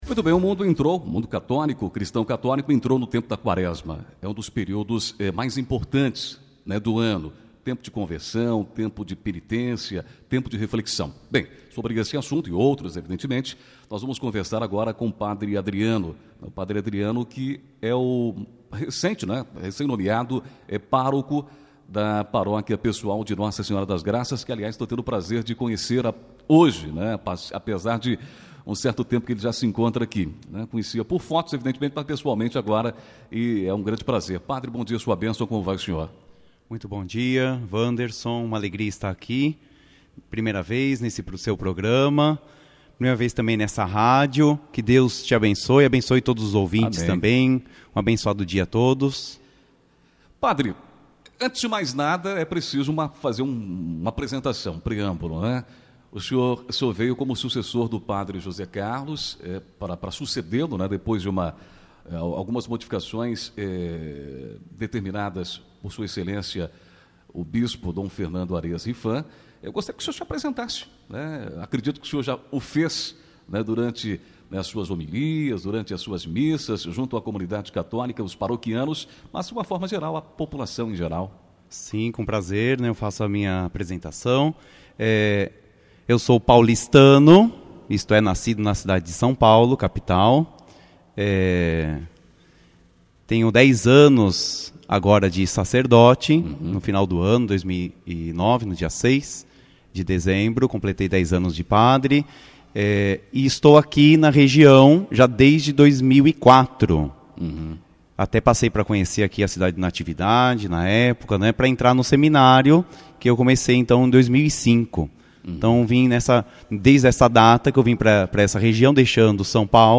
27 fevereiro, 2020 ENTREVISTAS, NATIVIDADE AGORA